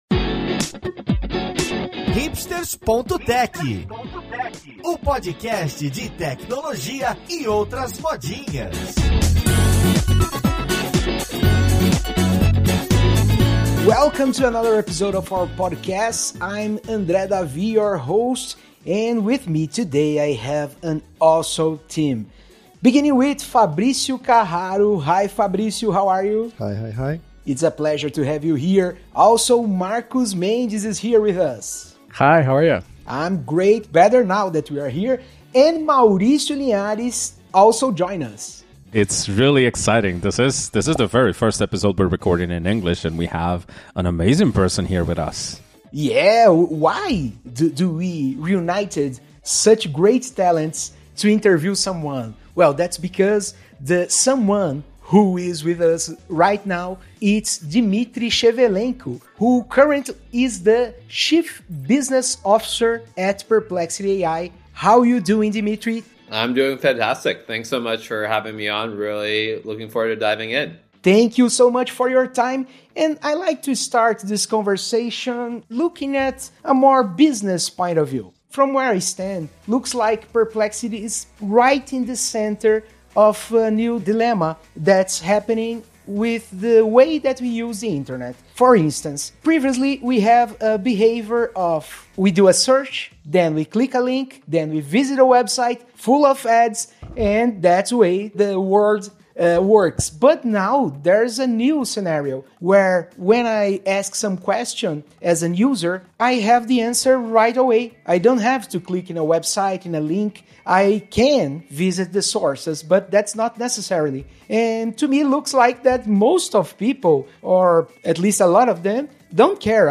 Entrevista original em inglês